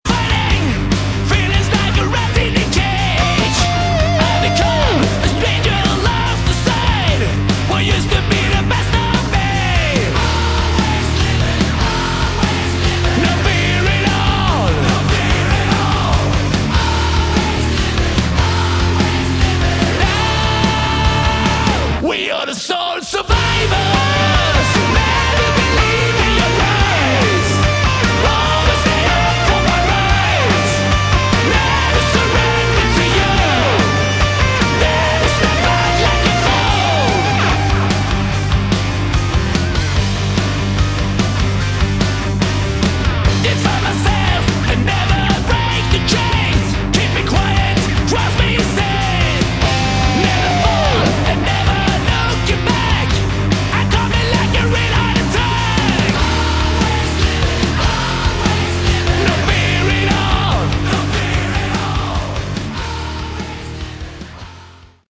Category: Hard Rock
lead vocals, rhythm guitar
bass, backing vocals
drums, backing vocals
lead guitar, backing vocals